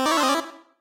Techmino/media/effect/chiptune/spawn_6.ogg at beff0c9d991e89c7ce3d02b5f99a879a052d4d3e